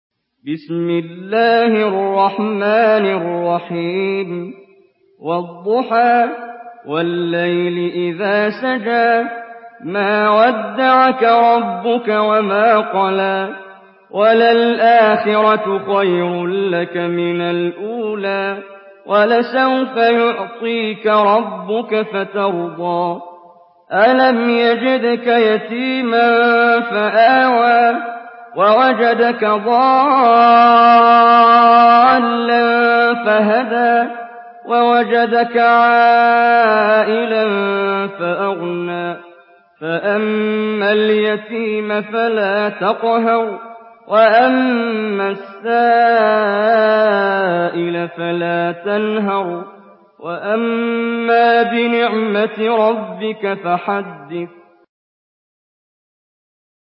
Sourate Ad-Duhaa MP3 à la voix de Muhammad Jibreel par la narration Hafs
Une récitation touchante et belle des versets coraniques par la narration Hafs An Asim.
Murattal Hafs An Asim